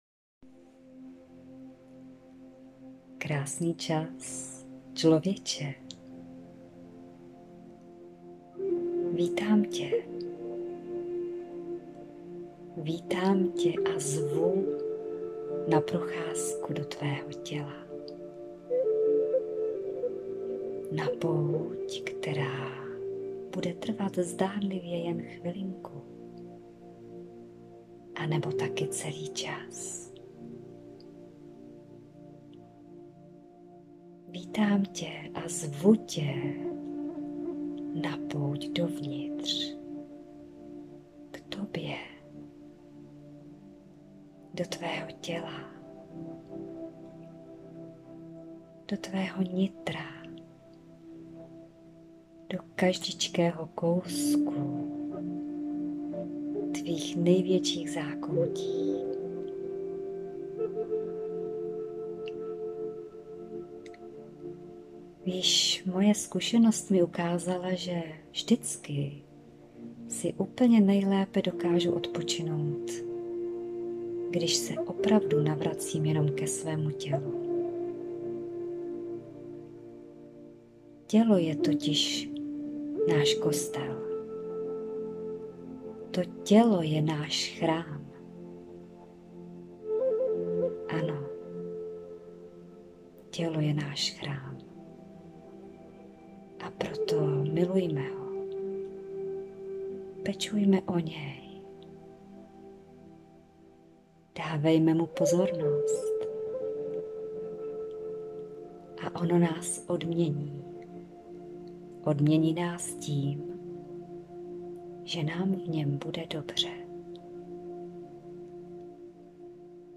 samaste-meditace-2024-1.mp3